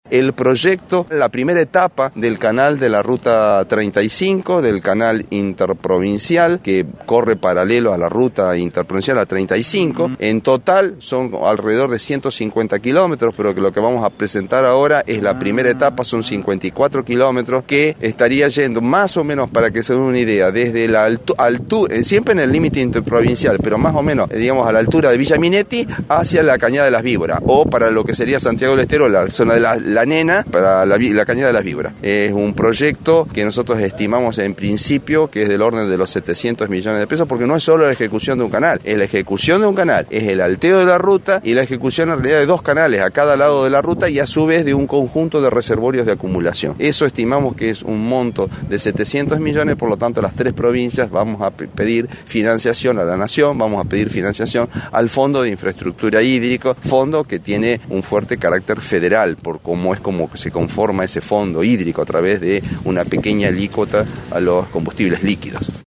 El encargado de la presentación de dicha iniciativa fue el secretario de Recursos Hídricos de Santa Fe, Ing. Juan Carlos Bertoni, quien lo describió brevemente: